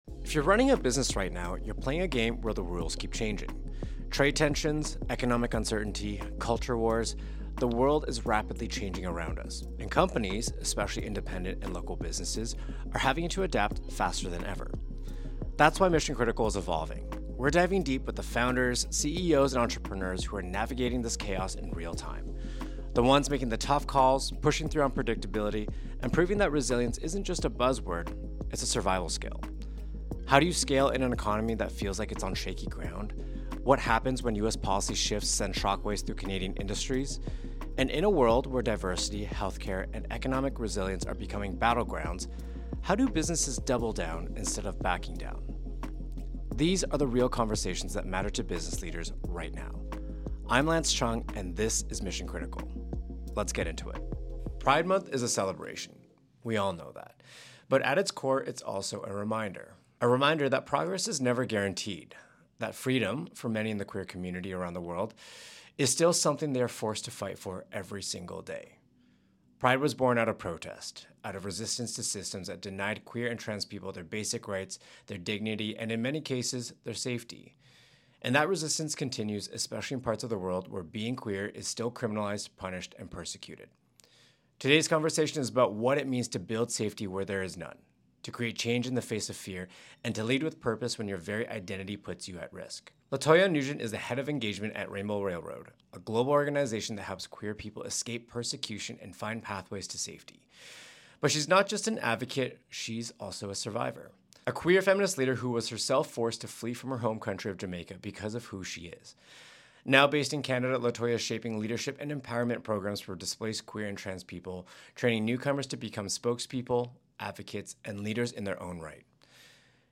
recorded live at IAA Mobility in Munich